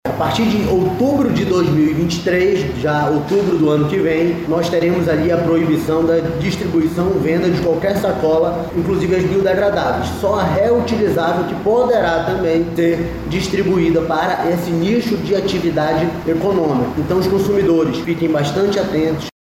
O diretor-presidente do Instituto de Defesa do Consumidor do Amazonas – Procon-AM , Jalil Fraxe, informa que tipo de produtos vão ser permitidos.